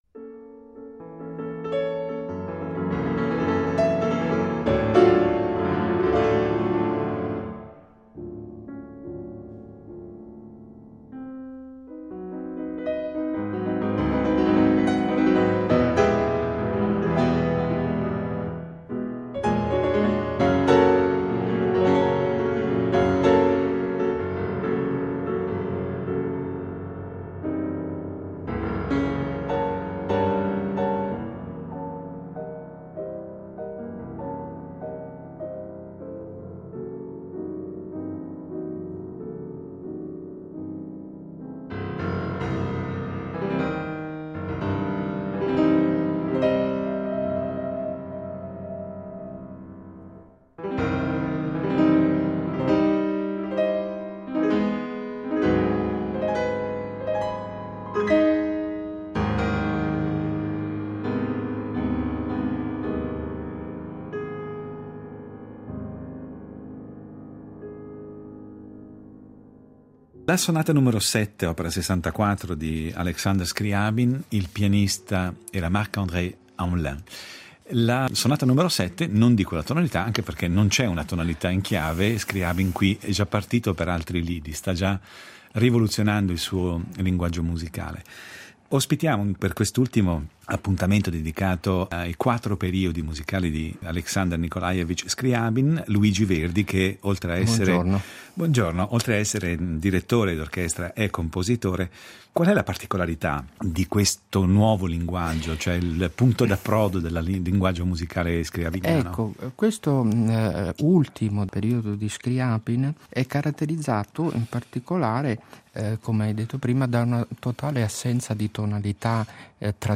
Le quattro età di Aleksandr Skrjabin. Incontro